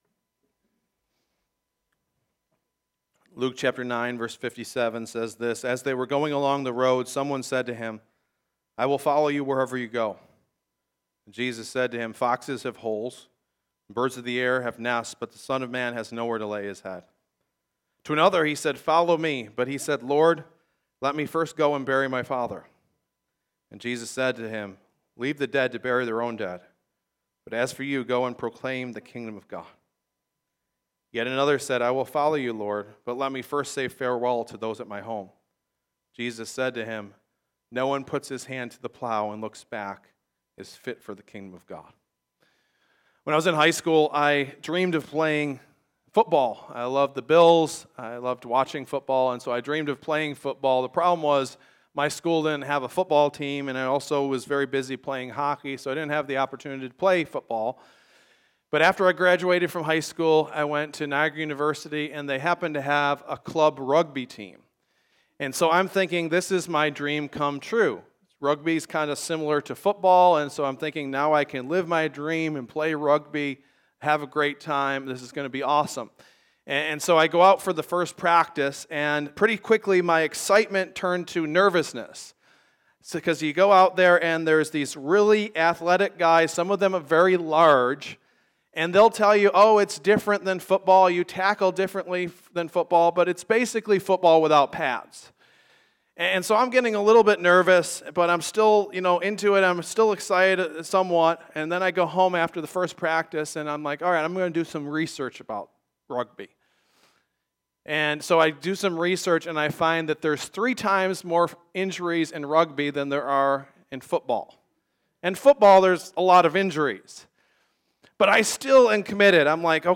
Message Luke 9.57-62.m4a